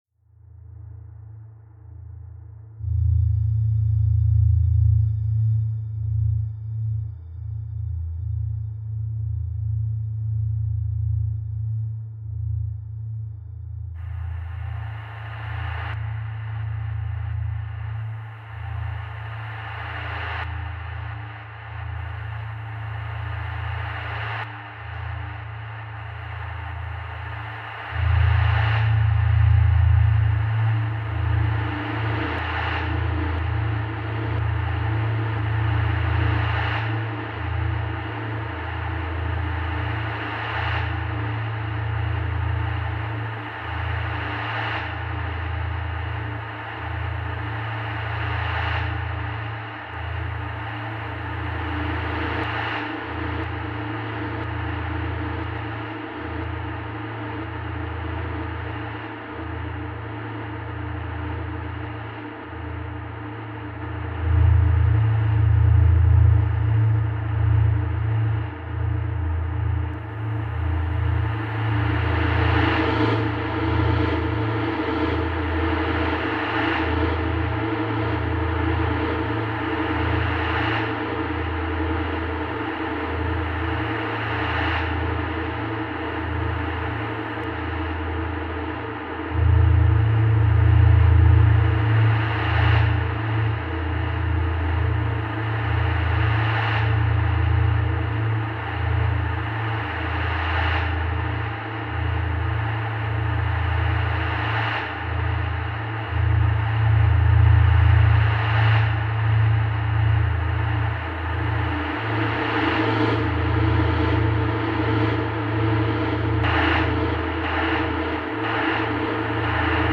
Exclusive Mirror Pool compositions: